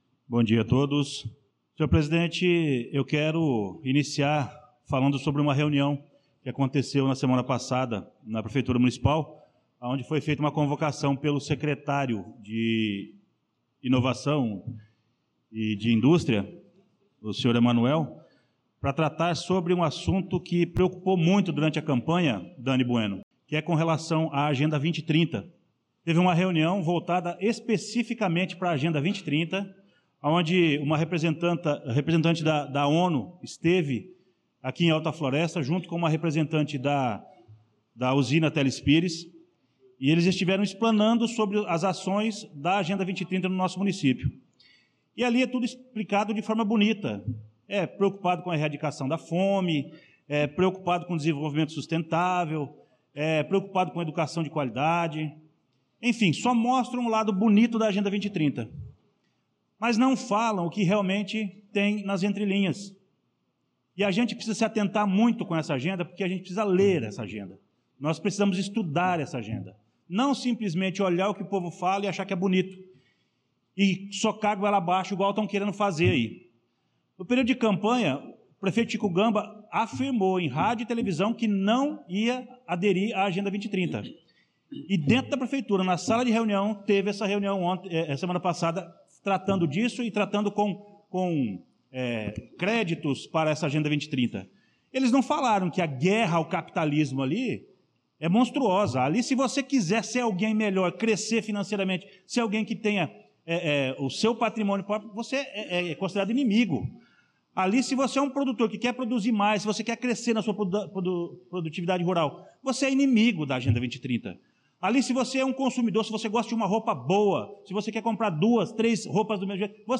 Pronunciamento do vereador Luciano Silva na Sessão Ordinária do dia 25/02/2025